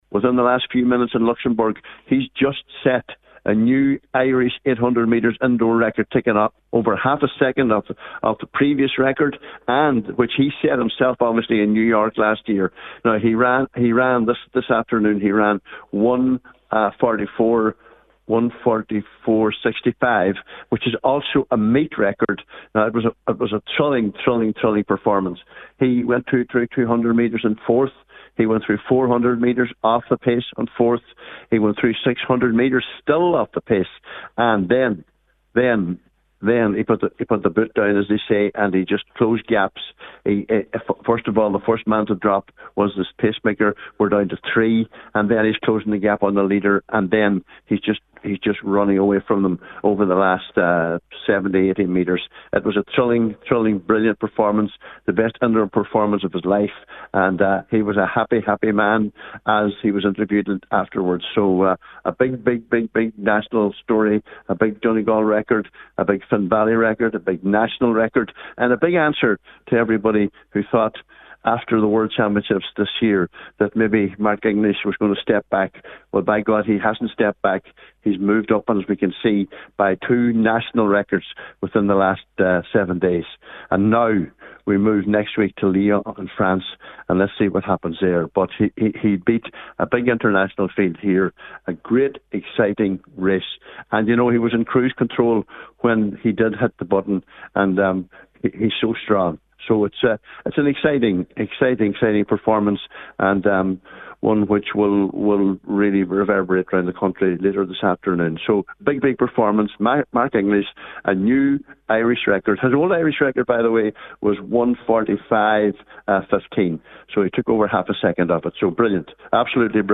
came to air just after the news came through from Luxembourg…